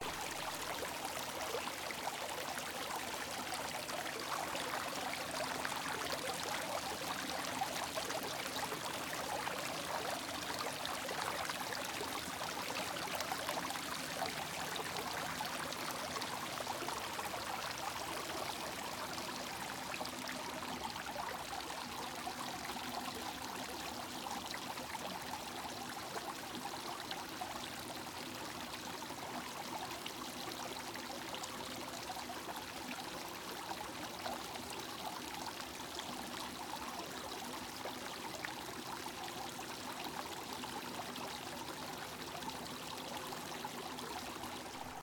Cgr Small Fountain.ogg